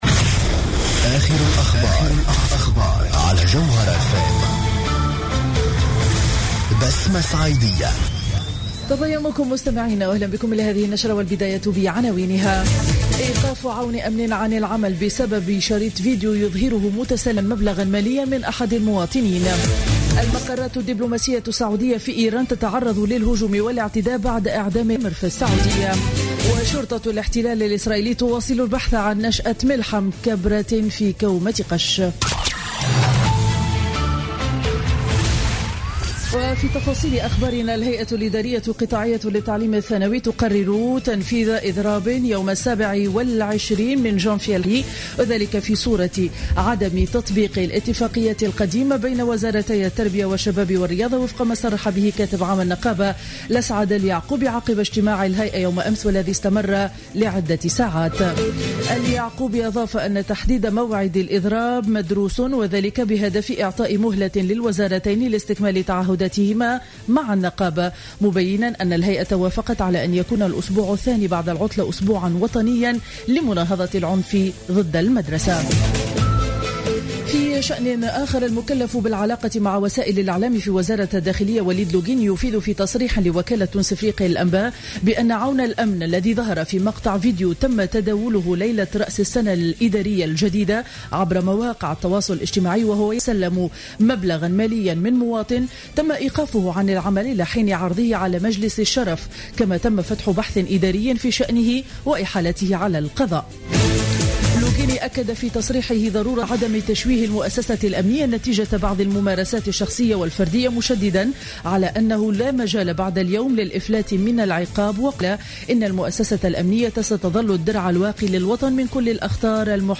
نشرة أخبار السابعة صباحا ليوم الأحد 03 جانفي 2016